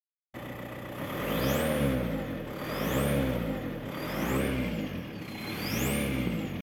Tema: Švilpimas realiai
Va kaip Skamba.